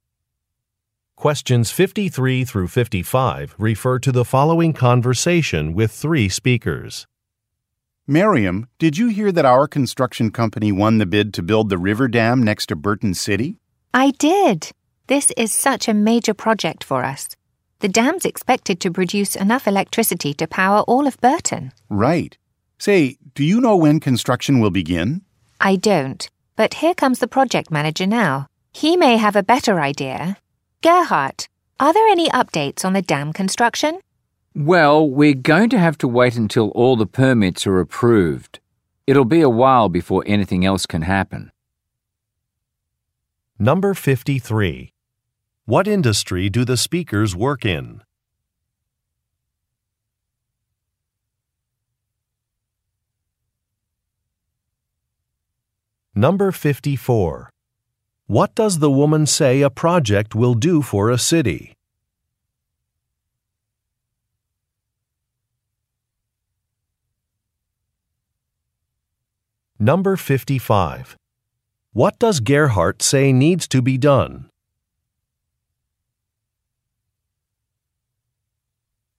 Question 53 - 55 refer to following conversation: